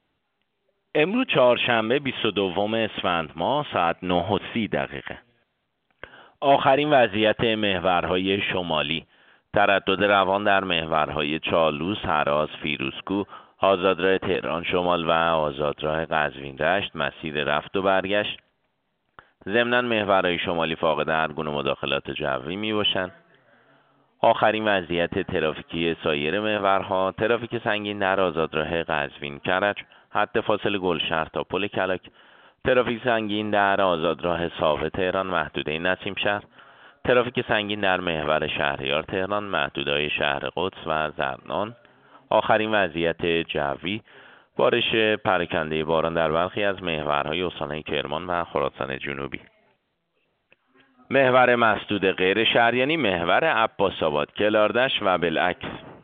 گزارش رادیو اینترنتی از آخرین وضعیت ترافیکی جاده‌ها ساعت۹:۳۰ بیست و دوم اسفند؛